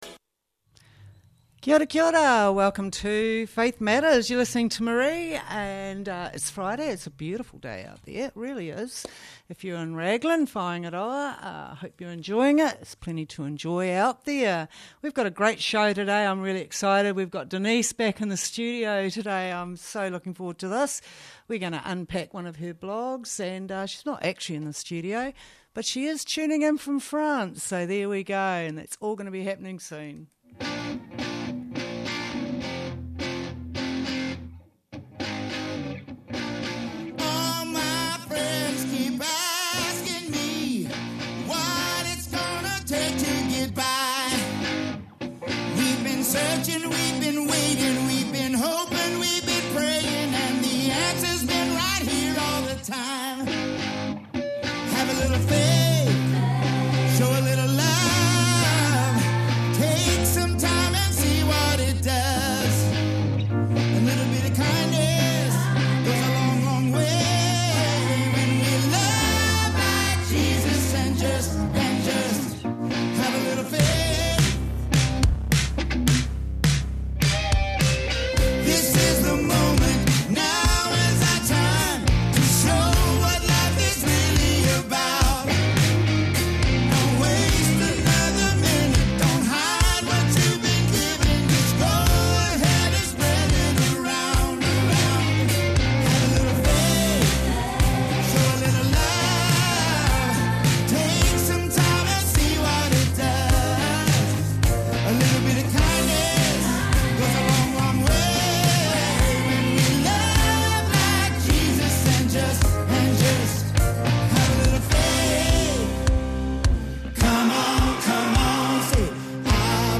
in the studio with me today tuning in all the way from France as we travel the roads of our internal map...are we travelling in autopilot ?... is its time for an update?!